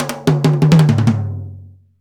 Index of /90_sSampleCDs/Roland - Rhythm Section/TOM_Rolls & FX/TOM_Tom Rolls
TOM TOM R00L.wav